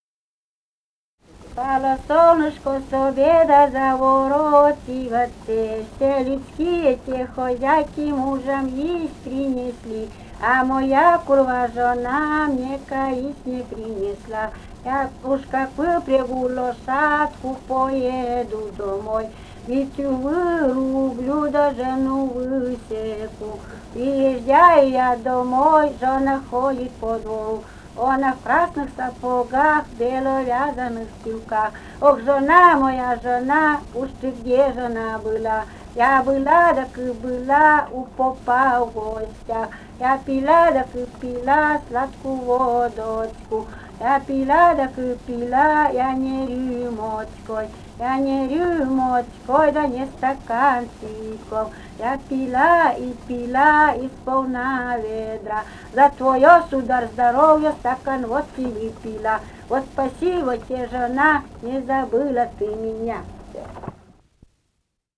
полевые материалы
Вологодская область, д. Малая Тигинского с/с Вожегодского района, 1969 г. И1130-10